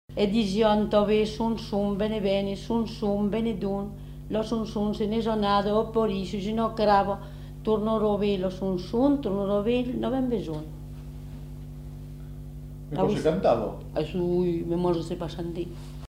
Aire culturelle : Périgord
Lieu : Castels
Genre : chant
Effectif : 1
Type de voix : voix de femme
Production du son : récité
Classification : som-soms, nénies